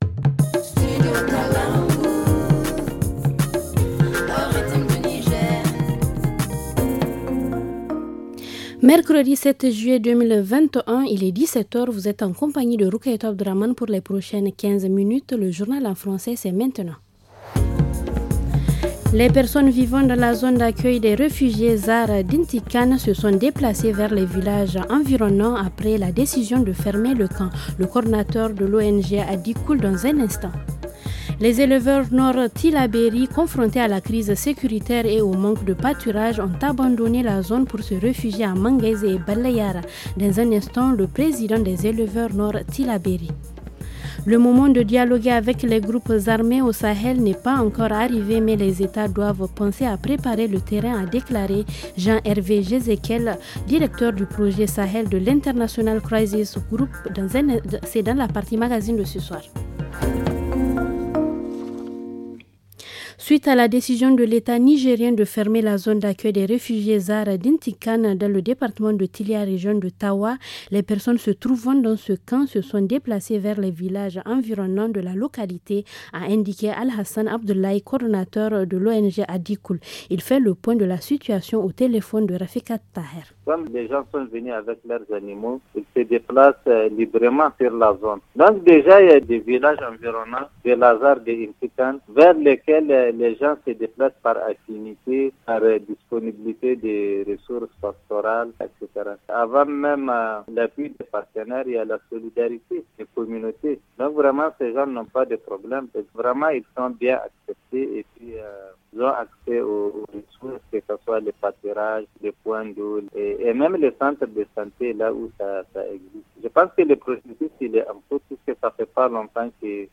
Le journal du 07 juillet 2021 - Studio Kalangou - Au rythme du Niger